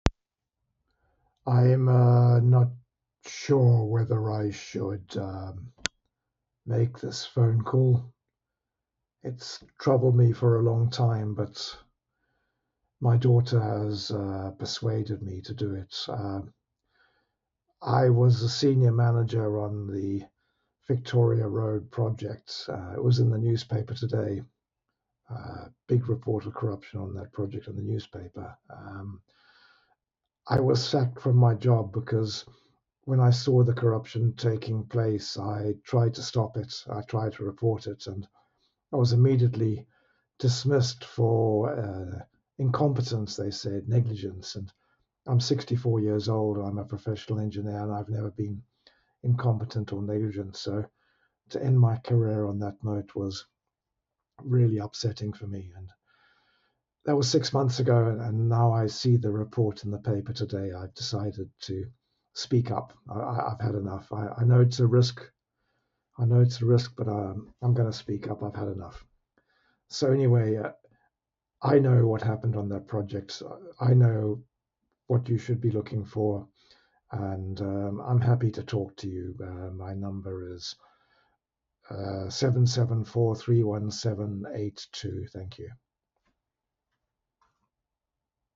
The Whistleblower Interview
Interview transcript